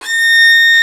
STR FIDDLE0A.wav